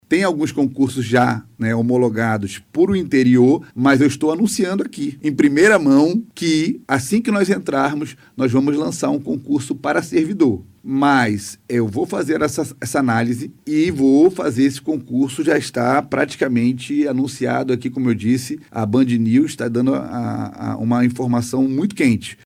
O anúncio foi feito durante entrevista à BandNews Difusora nesta segunda-feira (22) ao falar sobre os projetos de expansão da Defensoria.